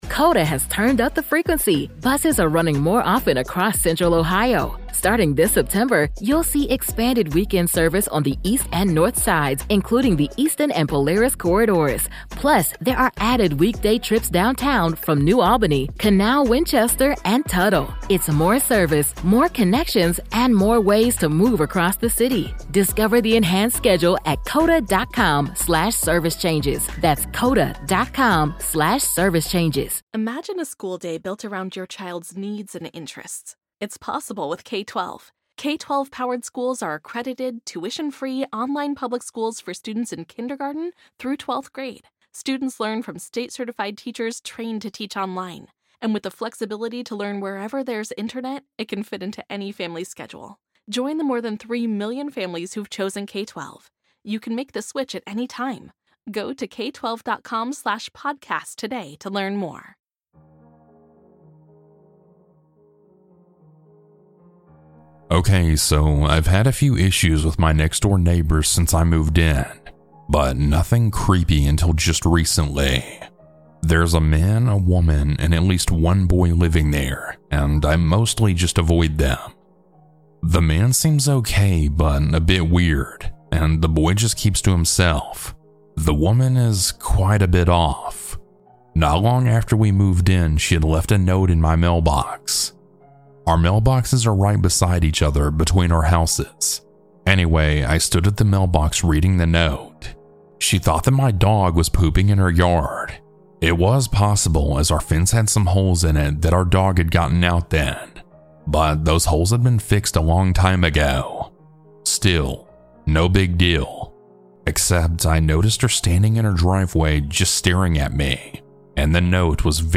Huge Thanks to these talented folks for their creepy music!